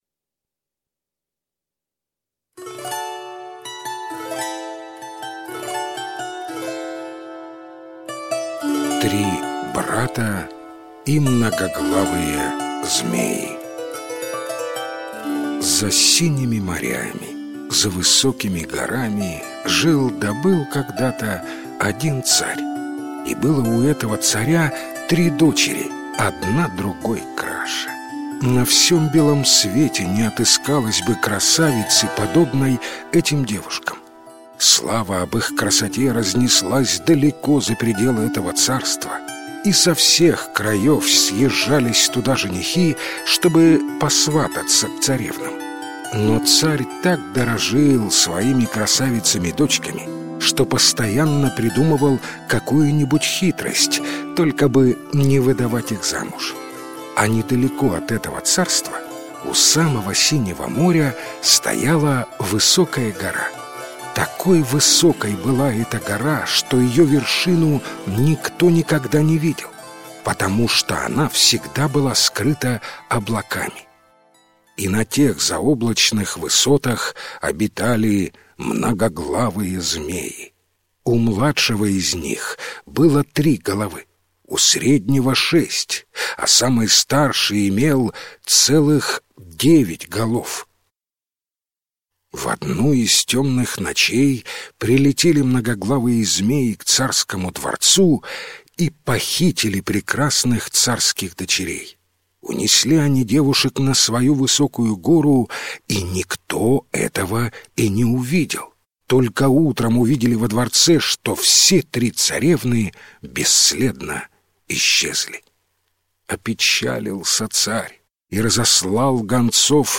Три брата и многоглавые змеи - украинская аудиосказка - слушать онлайн